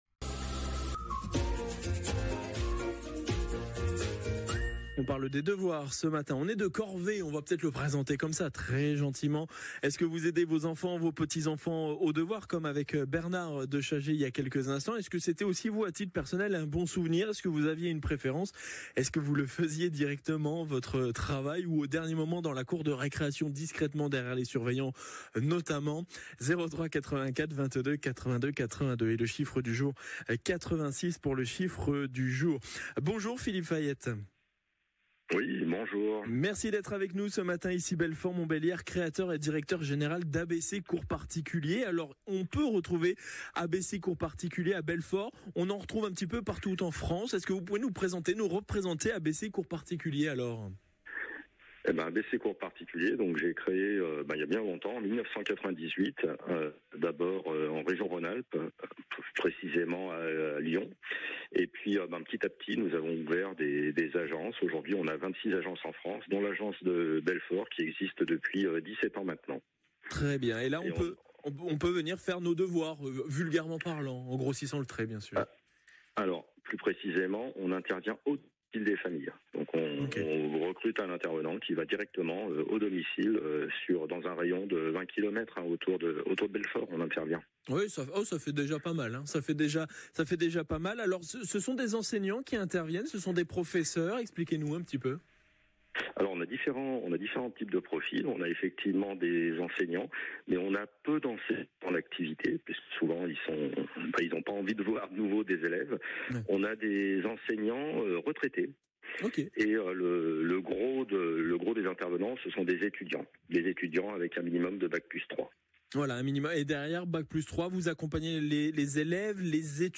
Notre organisme a été interrogé par France Bleu Belfort-Montbéliard, découvrez notre passage ici.
ABC-Cours-Particuliers-France-Bleu-Belfort-octobre-2025.mp3